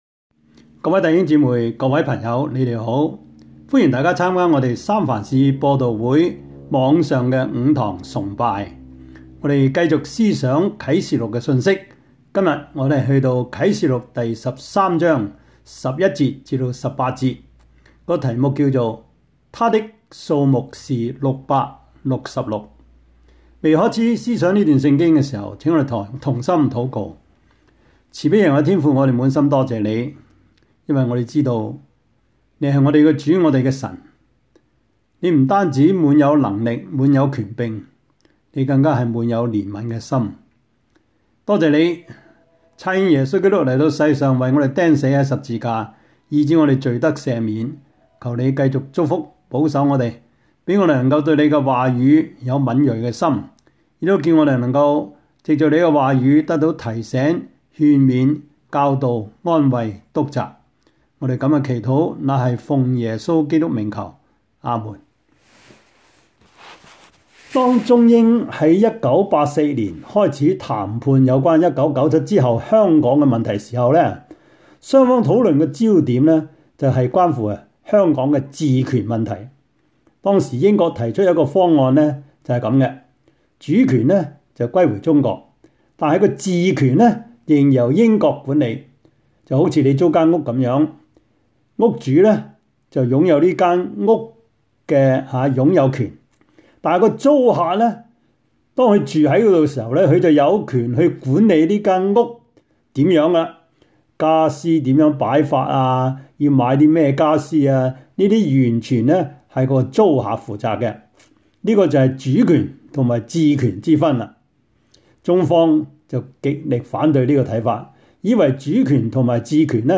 Service Type: 主日崇拜
Topics: 主日證道 « 第三十四課:愛爾蘭的宗教改革及悲劇 如何解開矛盾和衝突?